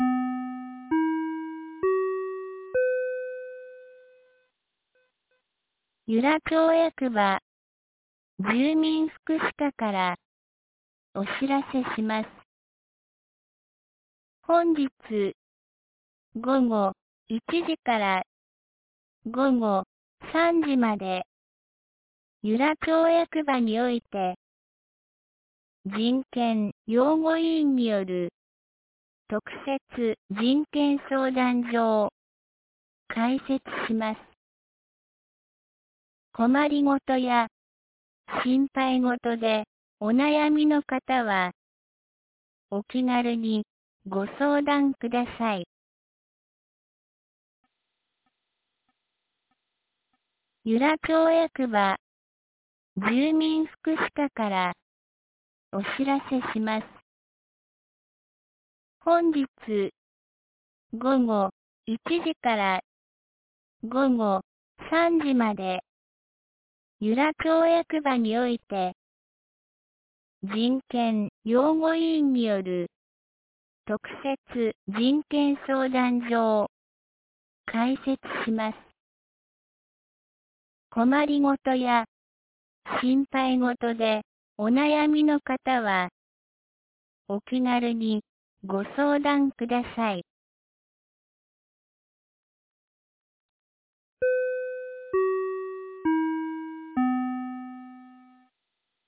2025年05月30日 07時52分に、由良町から全地区へ放送がありました。